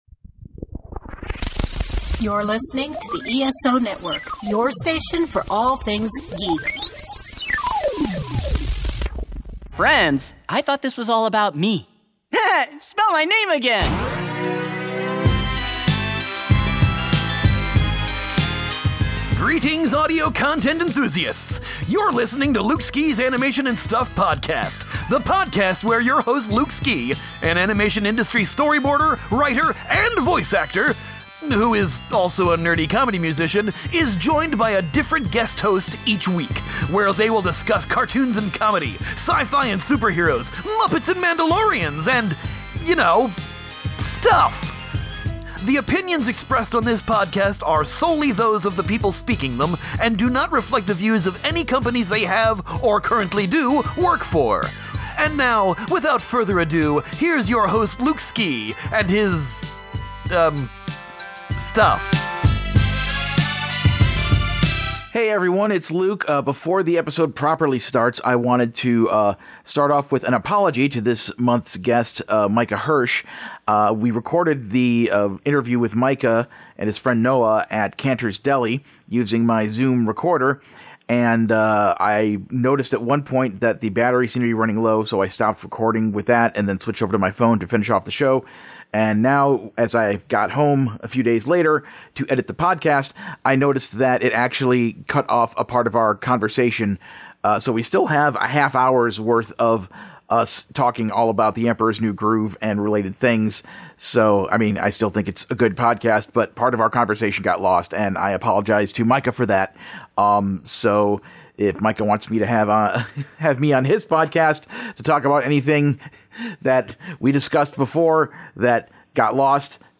and after the show we went to Canter’s Delicatessen on Fairfax to record this episode while having some good pastrami sandwiches!